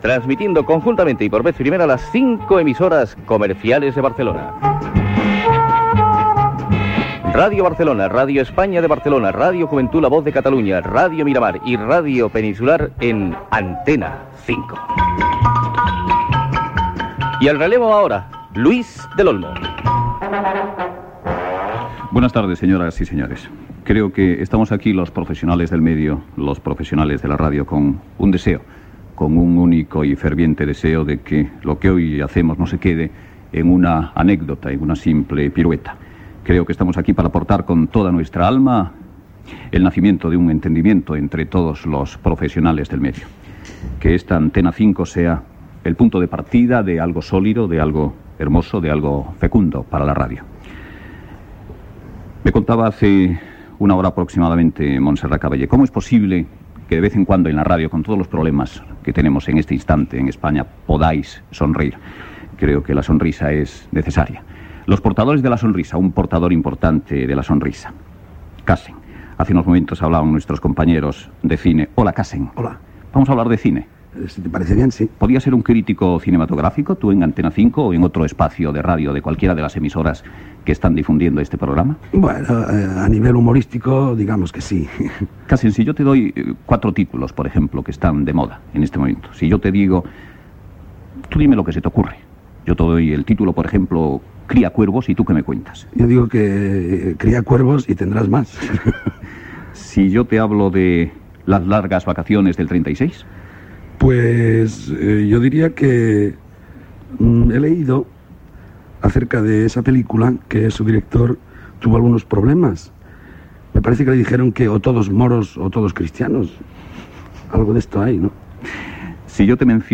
Salutació i entrevista a l'humorista Cassen sobre cinema, esports i l'ofici d'humorista.
Info-entreteniment